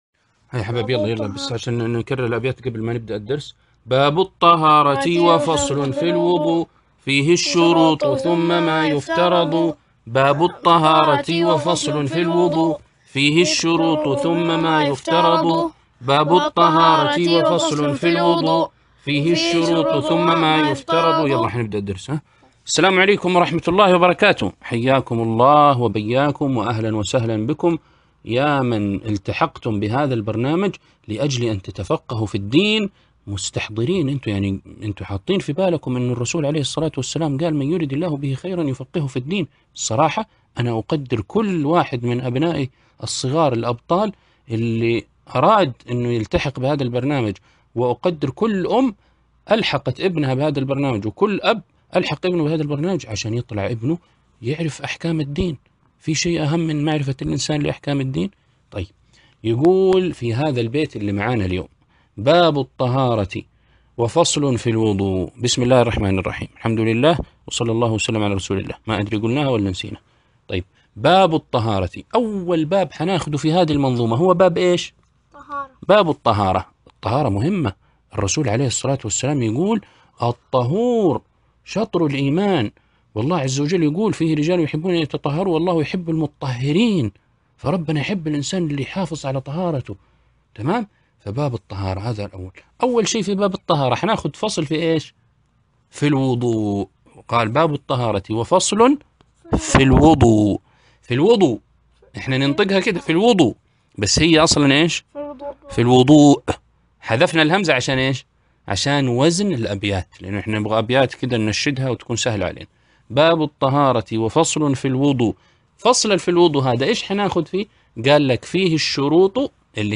عنوان المادة الدرس ( 5) شرح النظم الأصغر في الفقه للأطفال (الإصدار الأول 1439هـ)